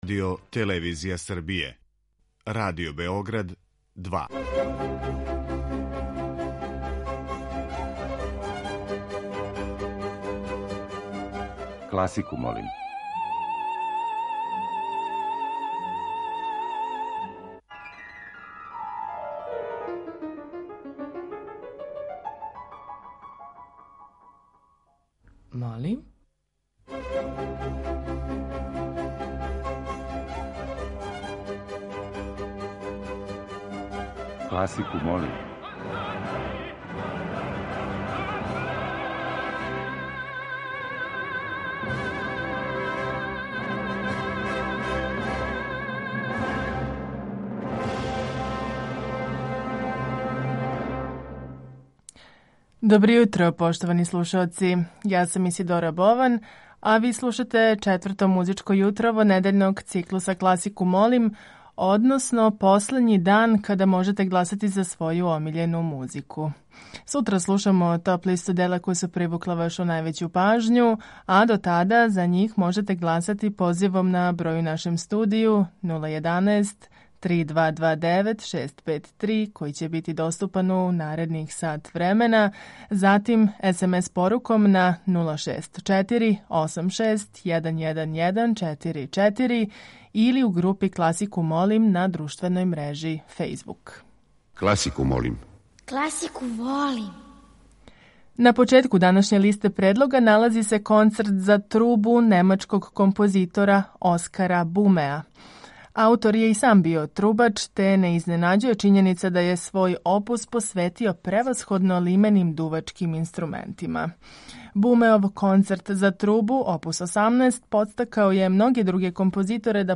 Уживо вођена емисија у којој се могу чути стилски разноврсна остварења класичне музике окренута је широком кругу слушалаца.